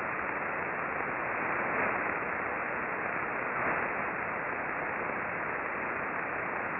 RFI with a popping sound is apparent in the recordings.
Click here for a mono recording of two L-bursts.